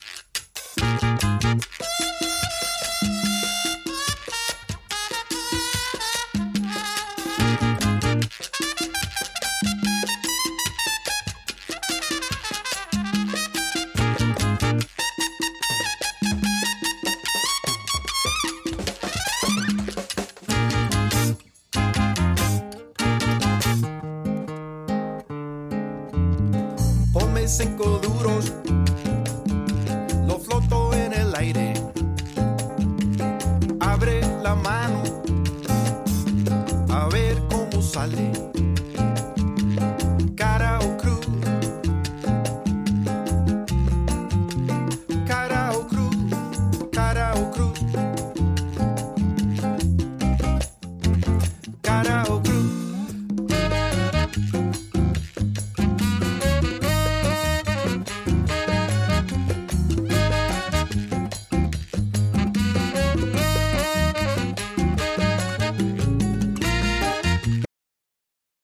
6 piece band
Great for dancing cumbia, meringue, twist.